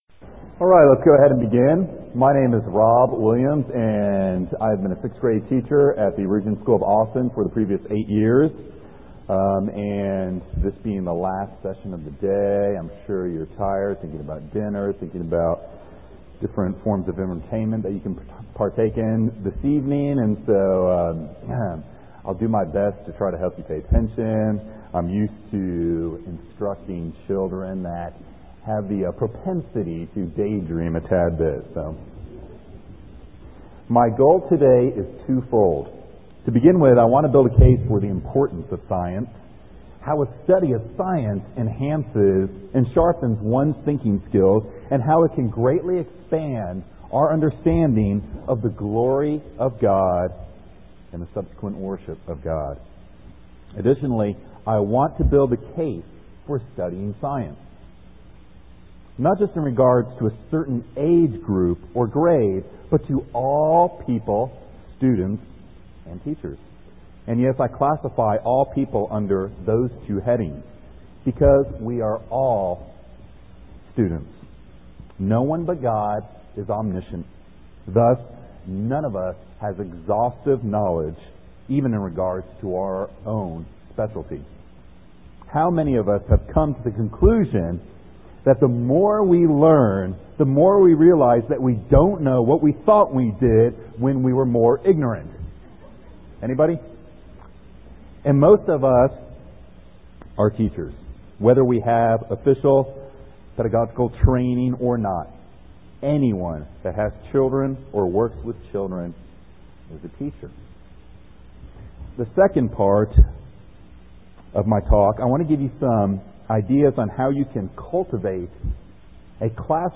Dec 20, 2018 | Conference Talks, Foundations Talk, K-6, Library, Science | 0 comments
The Association of Classical & Christian Schools presents Repairing the Ruins, the ACCS annual conference, copyright ACCS.